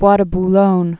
(bwä də b-lōn, -lônyə)